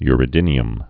(yrĭ-dĭnē-əm) also u·re·di·um (y-rēdē-əm)